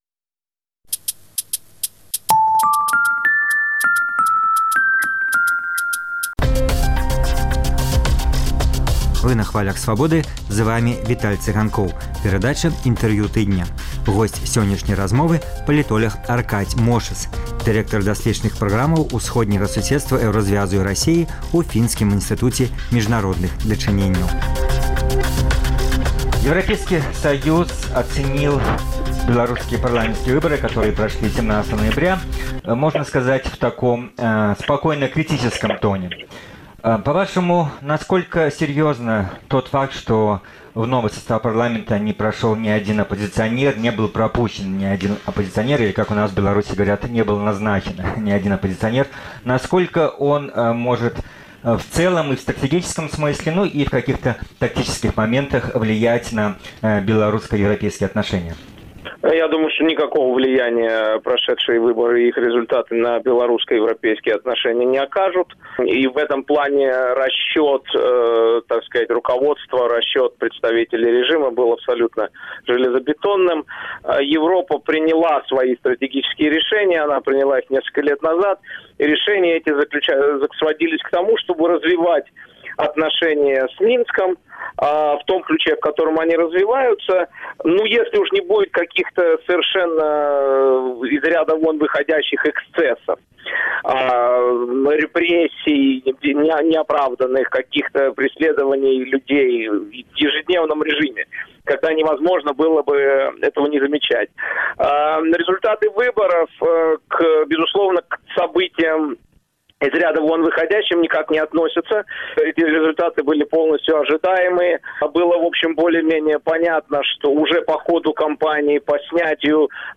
У праграме: Інтэрвію тыдня.